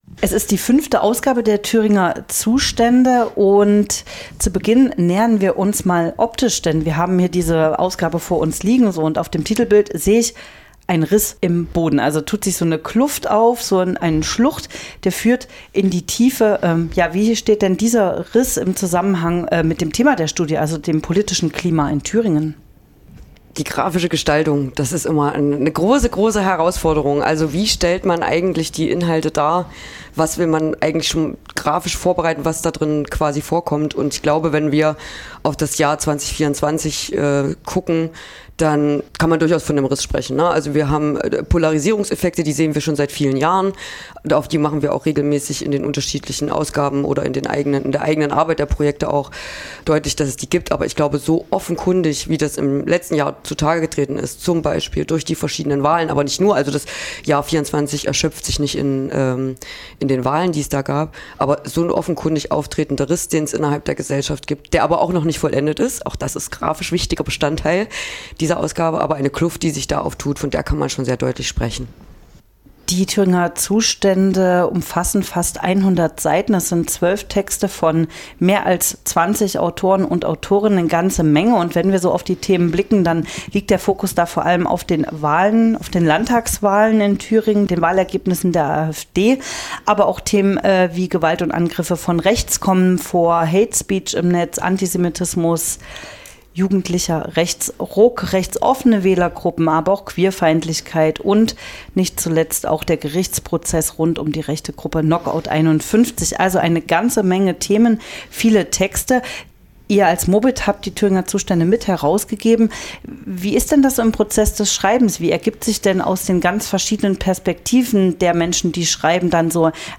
sprach Radio F.R.E.I. über die Ergebnisse der Studie